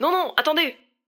VO_ALL_Interjection_02.ogg